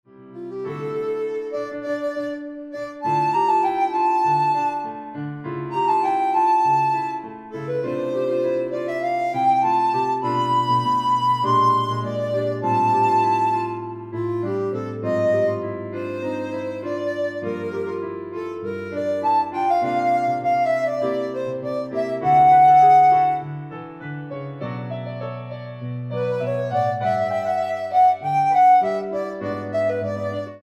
für Altblockflöte o.a. und Klavier (Jazzy Samba)
Jazz/Improvisierte Musik
Duo
Altblockflöte (1, oder Flöte), Klavier (1)